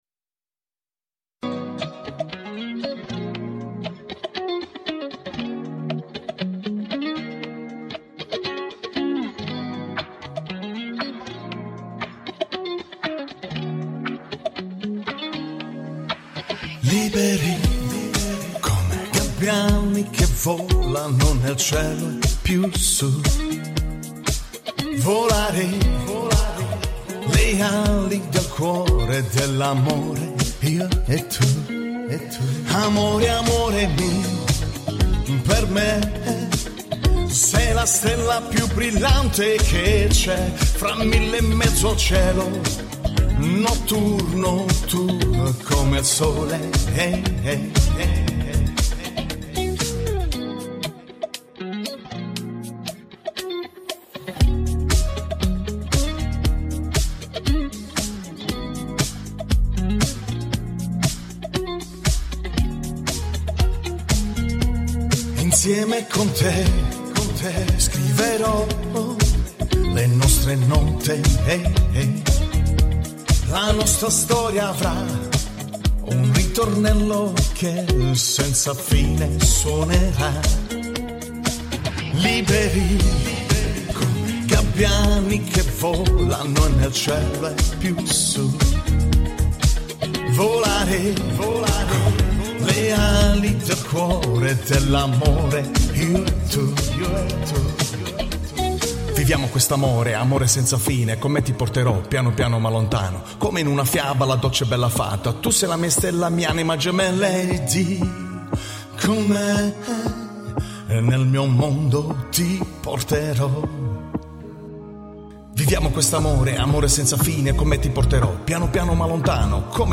Musica Italiana | Alleinunterhalter | Live Musik | Italienische Musik